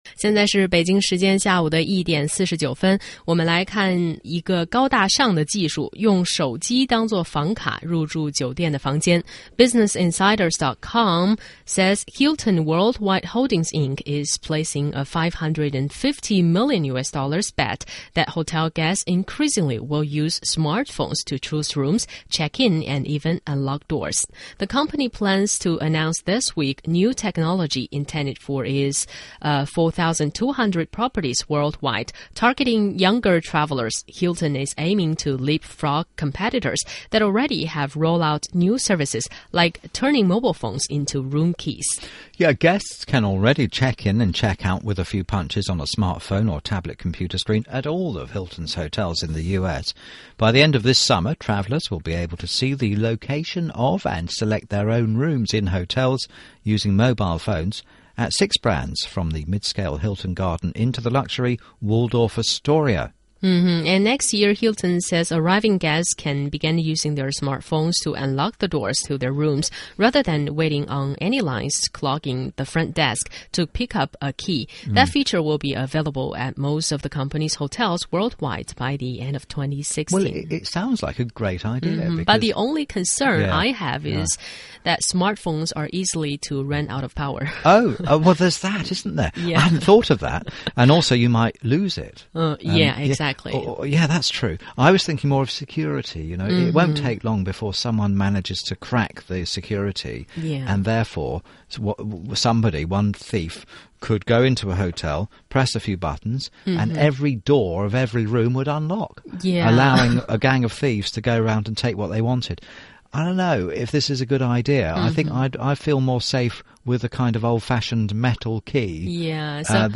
在线英语听力室双语趣听精彩世界(MP3+文本) 第60期:入住酒店,手机代替房卡的听力文件下载,《双语趣听精彩世界》栏目通过讲述中外有趣的故事，来从不同的角度看中国、看世界，是了解大千世界的极好材料。中英双语的音频，能够帮助提高英语学习者的英语听说水平，中外主持人的地道发音，是可供模仿的最好的英语学习材料,可以帮助英语学习者在轻松娱乐的氛围中逐渐提高英语学习水平。